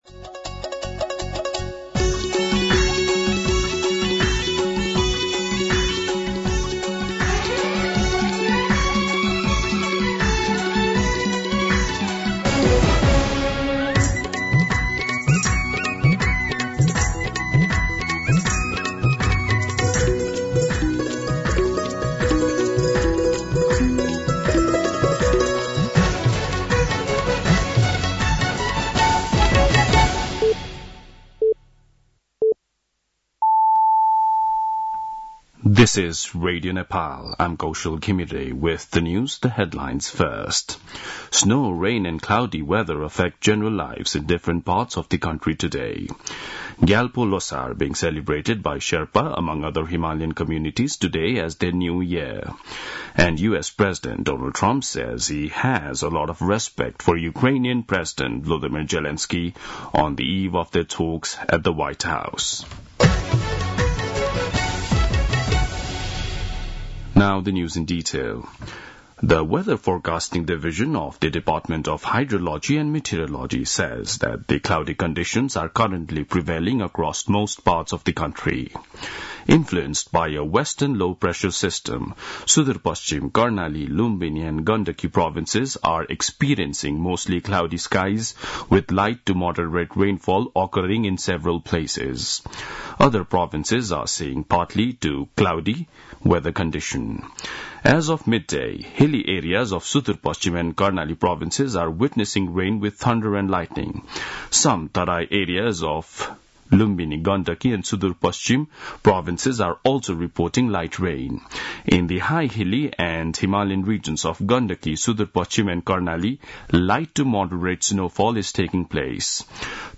दिउँसो २ बजेको अङ्ग्रेजी समाचार : १७ फागुन , २०८१
2-pm-English-News-1.mp3